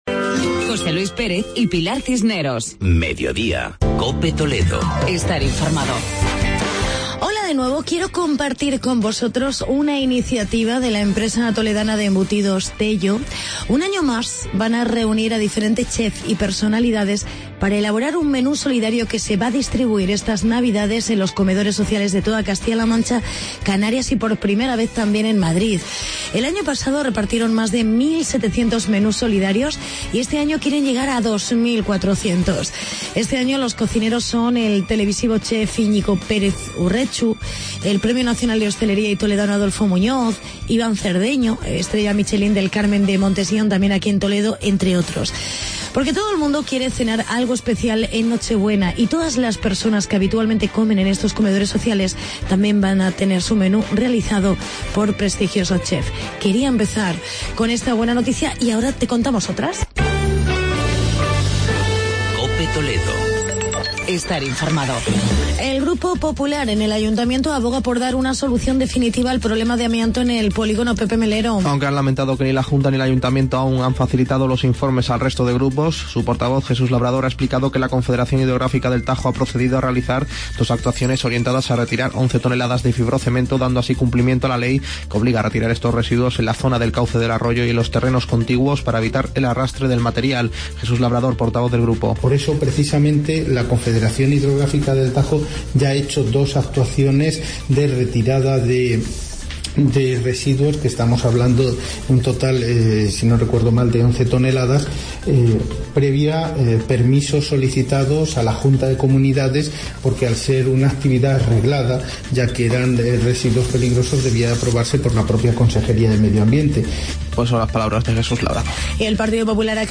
Actualidad y entrevista con el concejal de hacienda del ayuntamiento de Talavera, Arturo Castillo, hablando de Presupuestos 2017.